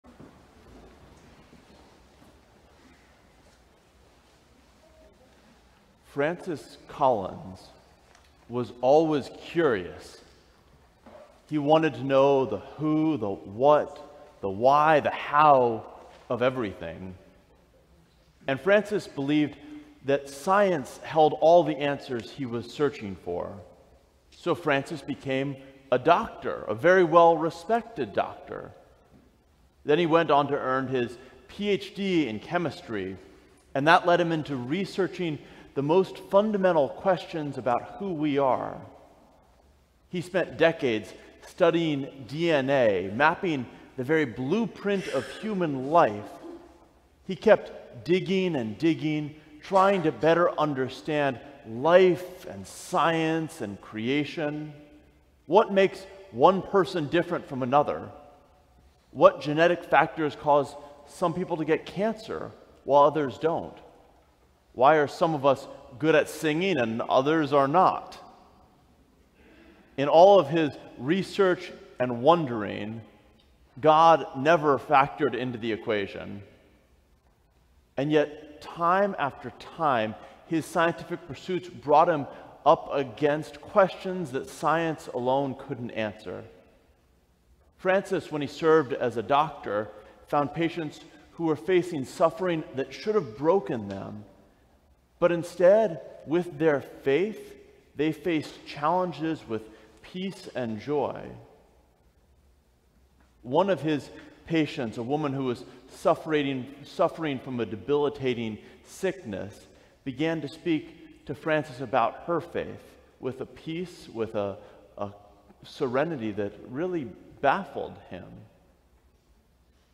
Sermon: The Question that Changes Everything - St. John's Cathedral
Christ the King Sunday, November 24, 2024